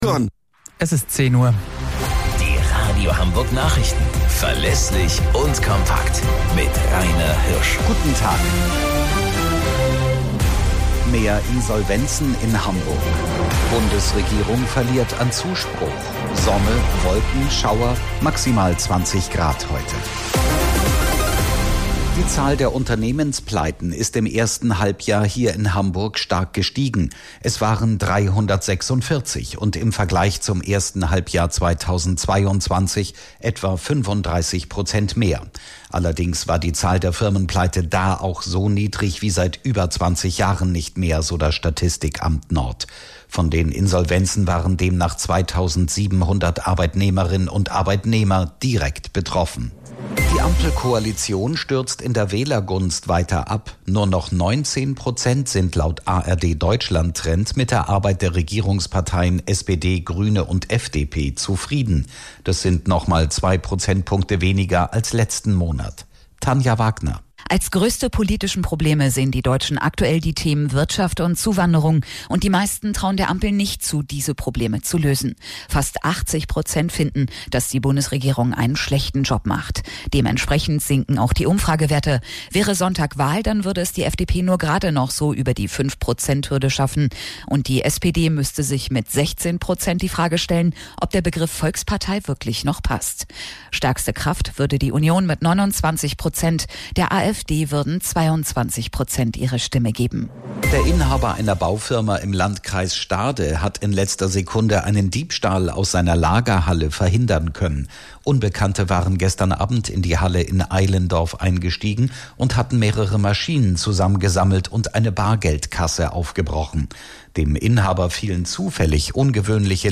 Radio Hamburg Nachrichten vom 01.09.2023 um 17 Uhr - 01.09.2023